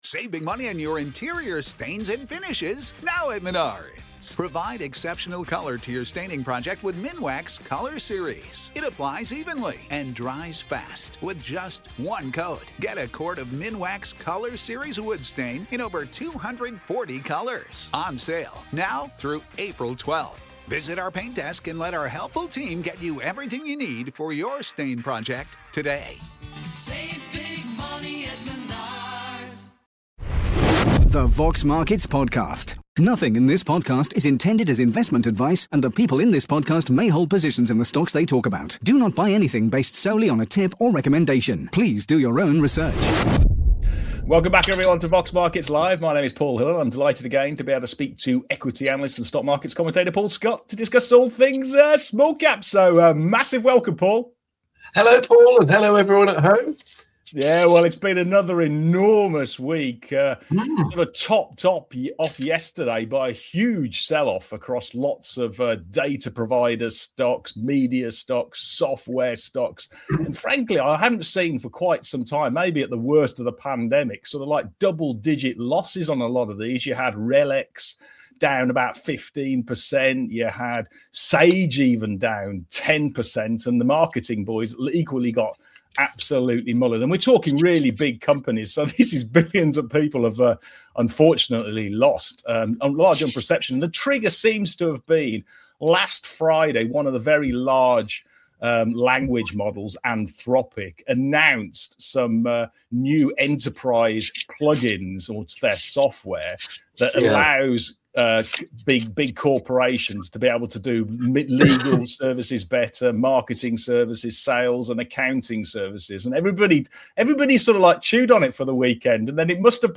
In this week’s fast-paced smallcap show